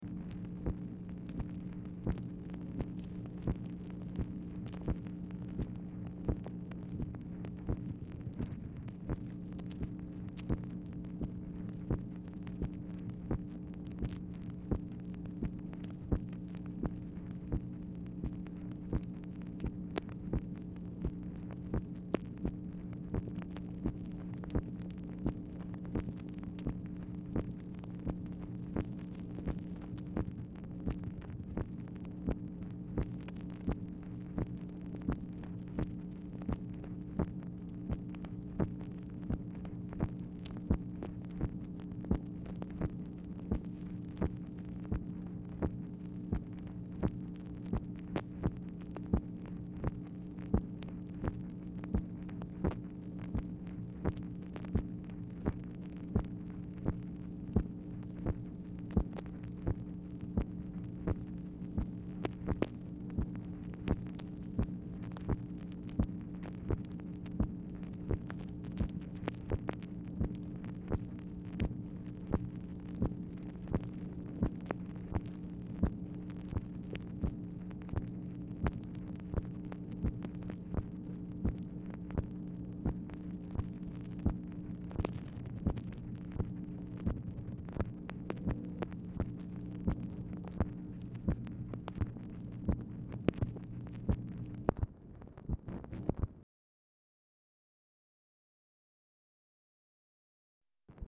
MACHINE NOISE
Oval Office or unknown location
Telephone conversation
Dictation belt